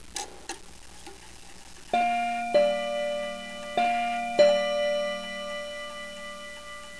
It's supposed to chime on 3 rods, but one broke off, and I still have not replaced it yet.
It's an 8-day English clock made by the Smith's Empire Clock company.
The chime is 'bim-bam' style and rings on the hour and half-hour.